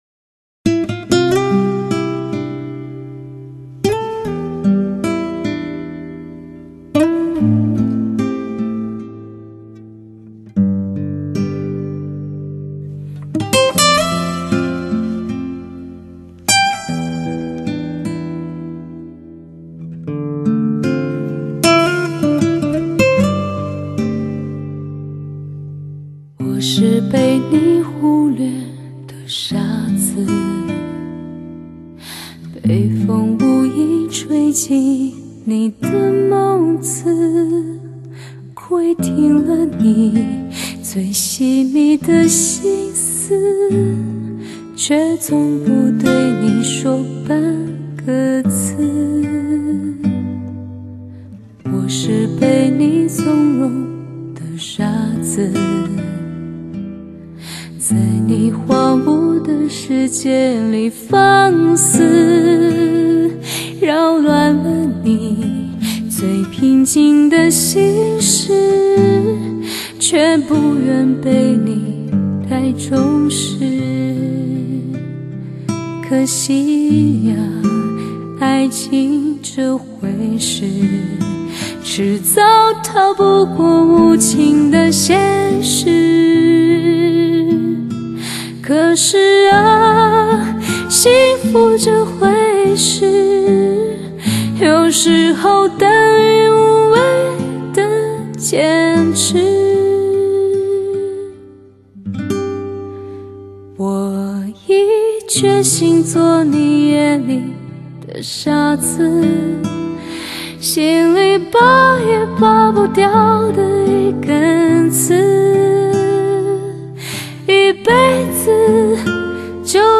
乐器：二胡 吉它 长笛 竹埙 古筝 笛子 小提琴 弹拨尔 艾捷克
悦耳的弹拨尔 优美深情的艾捷克 悠扬的小提琴 悲悯的二胡 闲情的古筝 深沉的竹埙……